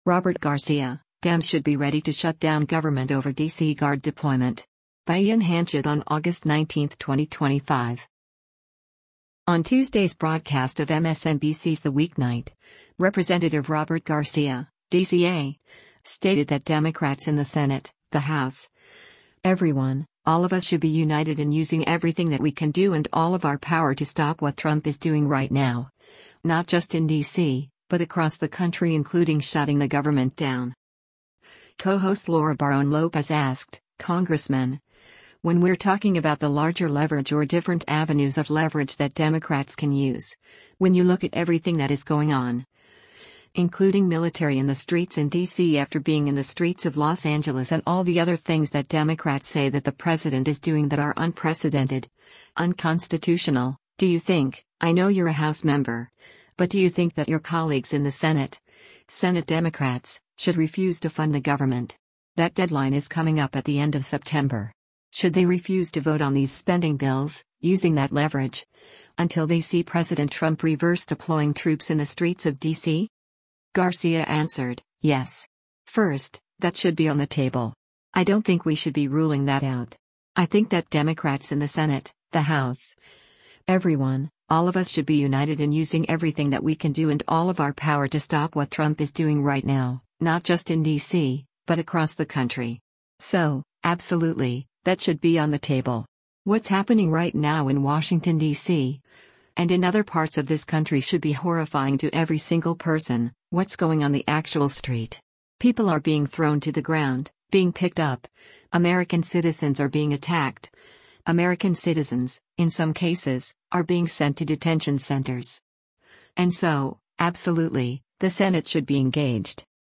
On Tuesday’s broadcast of MSNBC’s “The Weeknight,” Rep. Robert Garcia (D-CA) stated that “Democrats in the Senate, the House, everyone, all of us should be united in using everything that we can do and all of our power to stop what Trump is doing right now, not just in D.C., but across the country” including shutting the government down.